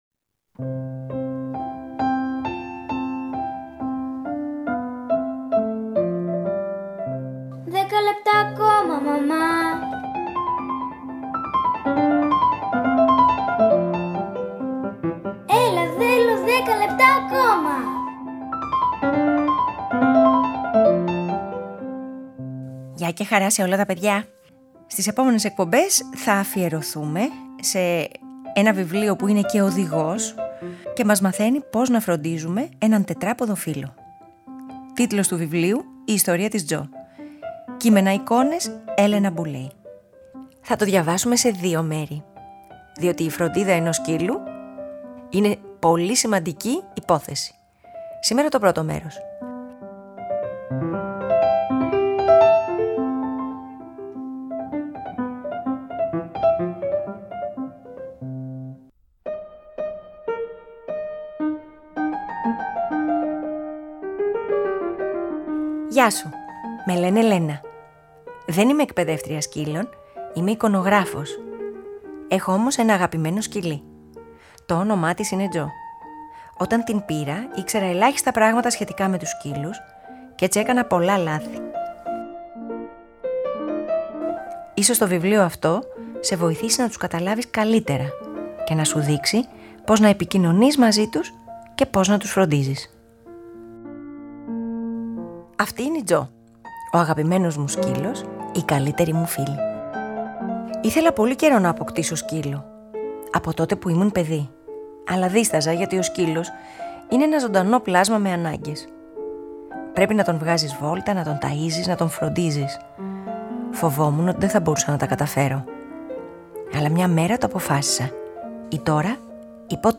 Ένα βιβλίο αφιερωμένο στο πώς να φροντίζουμε τους τετράποδους φίλους μας. Αφήγηση-Μουσικές επιλογές: